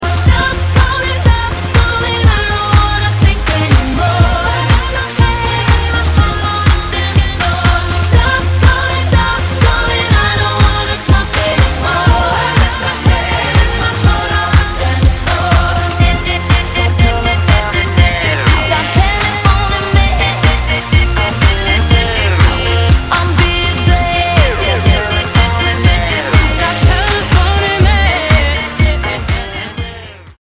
Две поп-дивы